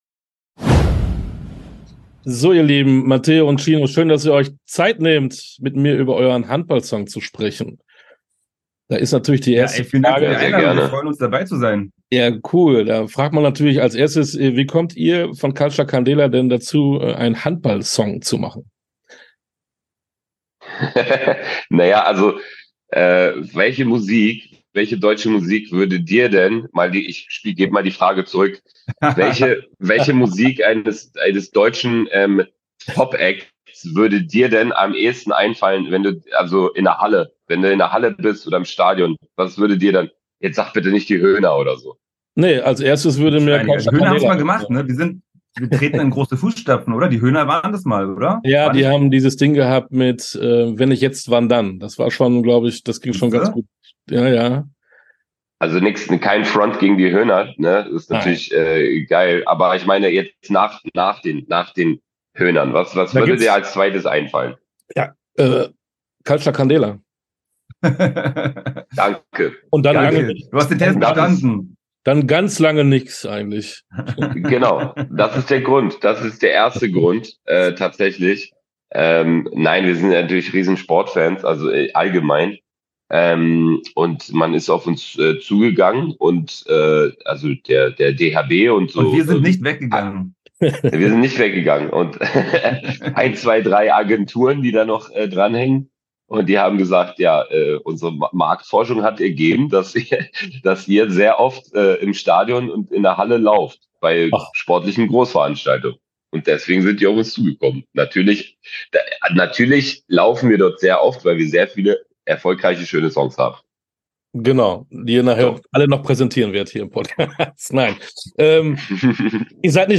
Interview_komplett_Culcha_Candela.mp3